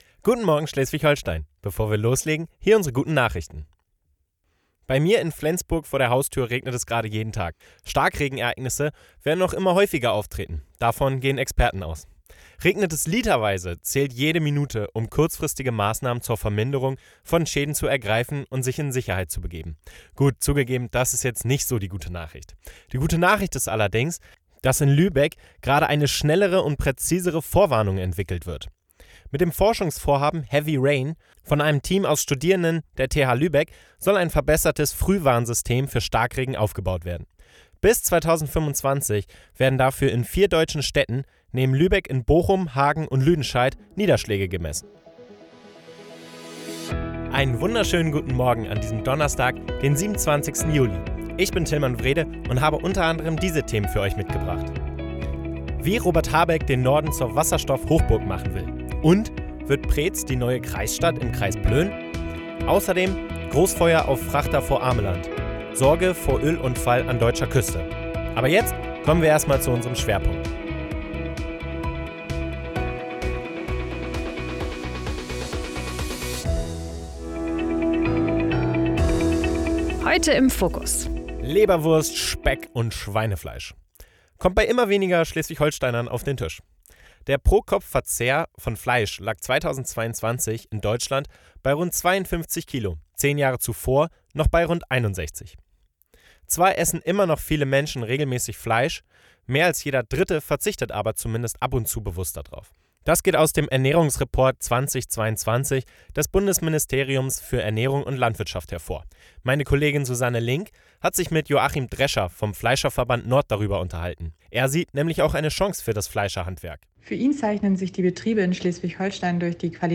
Dein News-Podcast für Schleswig-Holstein
Nachrichten